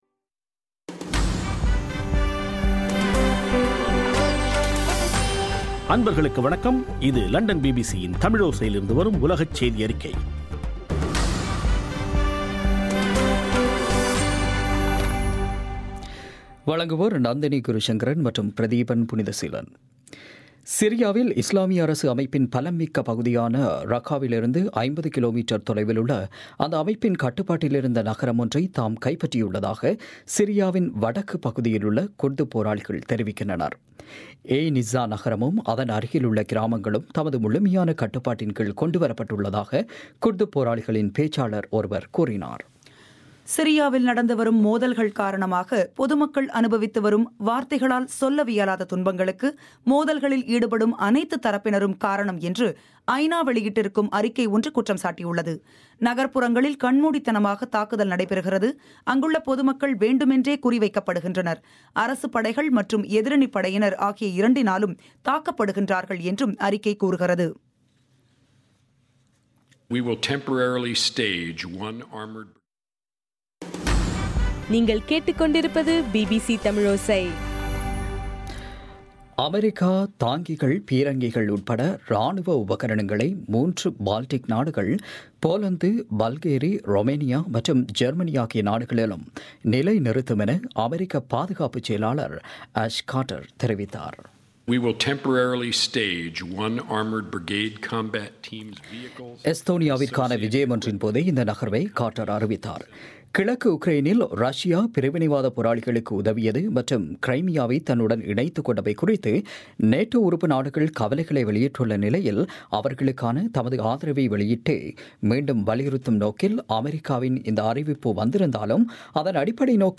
ஜூன் 23 பிபிசியின் உலகச் செய்திகள்